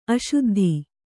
♪ aśuddhi